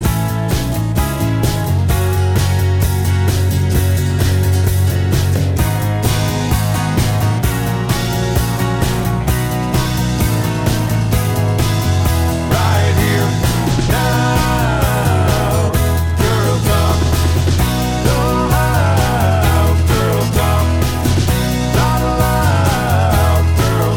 2 Semitones Down Pop (1970s) 3:31 Buy £1.50